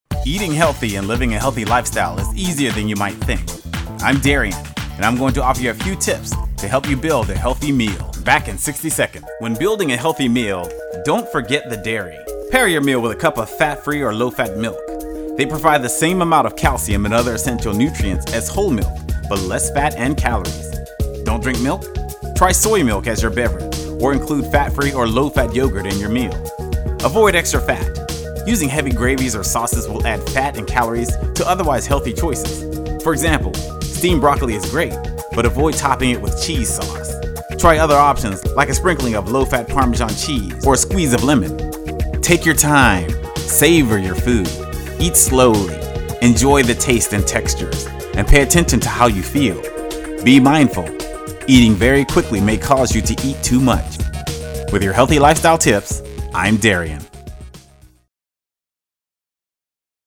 this daily two-minute radio feature